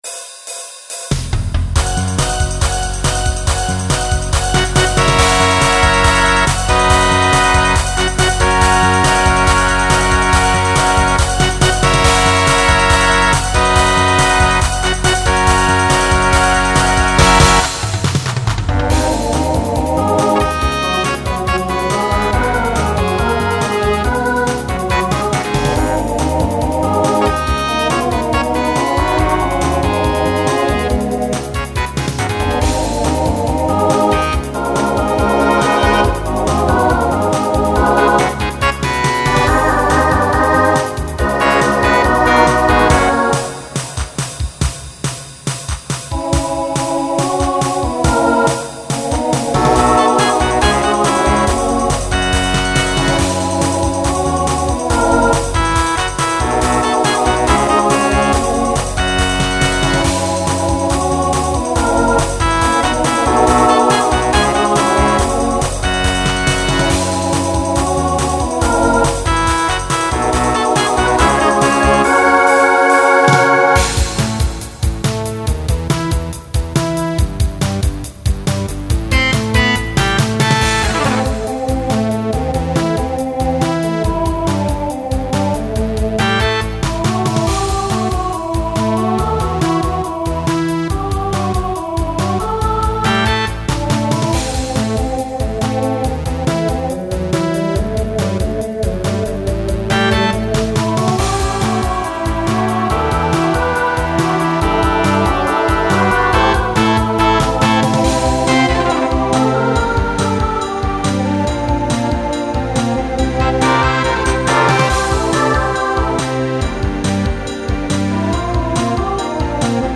Broadway/Film , Rock
Story/Theme Voicing SATB